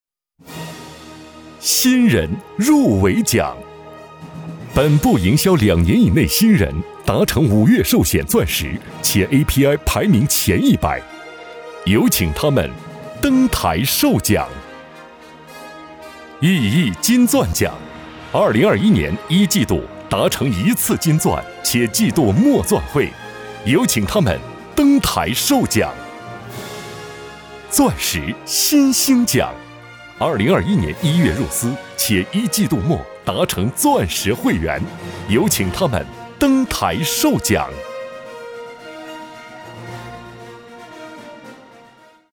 A男76号